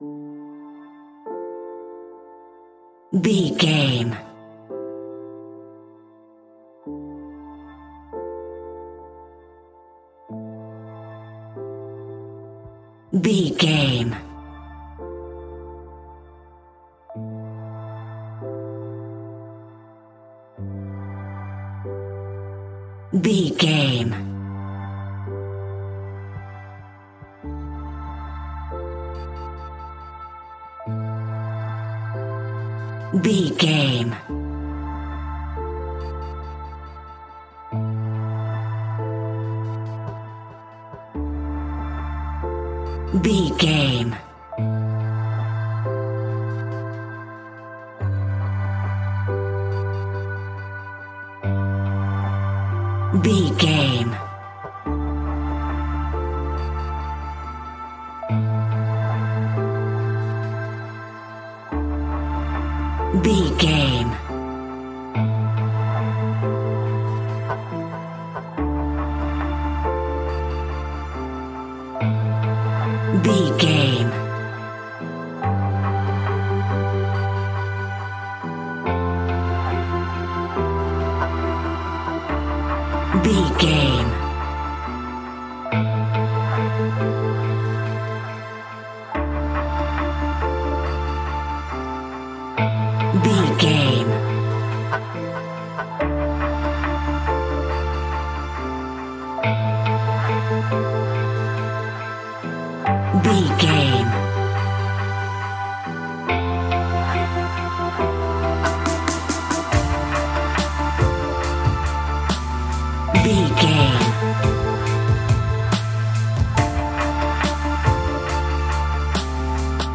Aeolian/Minor
Slow
ambient
dreamy
ethereal
melancholy
mellow
synthesiser
piano